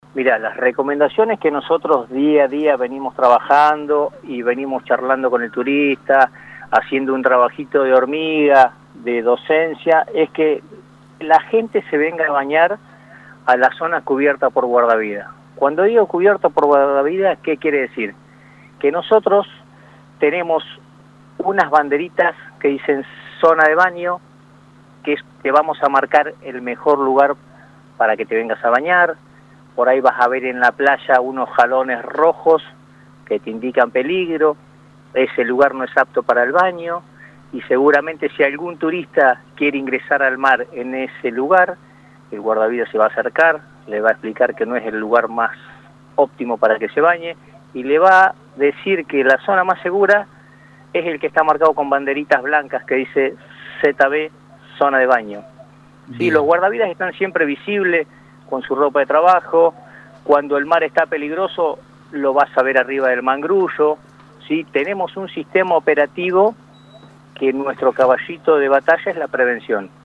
En conversación con LU24